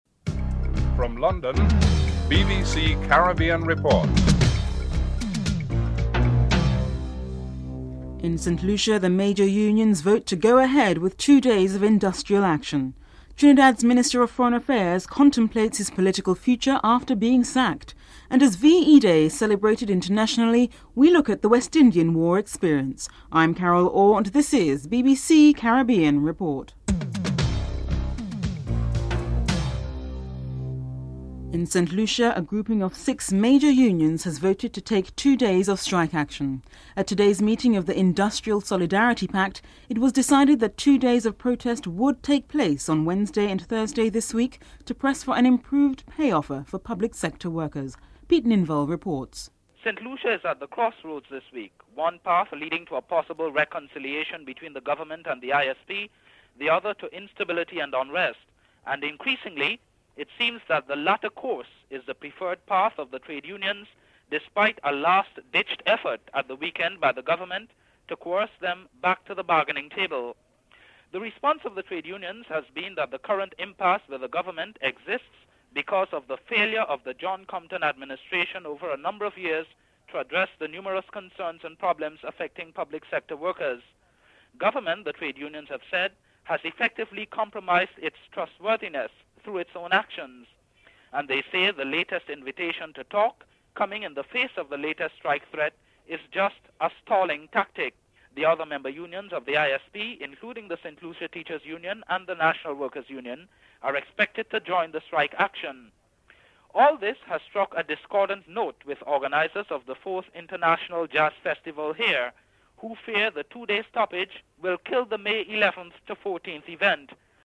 Jamaica's Governor General Sir Howard Cooke comments on whether he was disappointed that only two invitations had been sent to the Caribbean for attendance at the celebrations.
The report concludes with comments from Caribbean people on the relief felt when they heard the news that Hitler had been defeated.